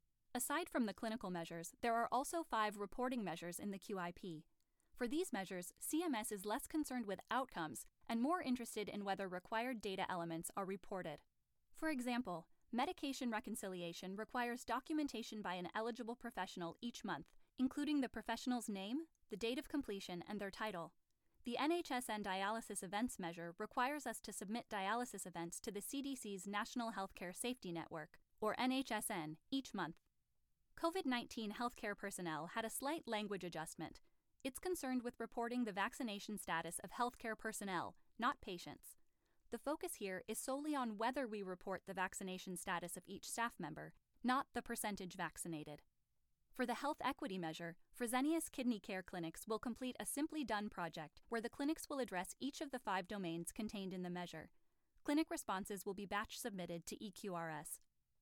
My natural speaking voice is the classic 'non-announcer' (conversational, friendly, natural, warm, and smooth) - well-suited for commercials - but it's also informative, intelligent, and professional, and can enliven even the driest educational content.
VOICE ACTOR DEMOS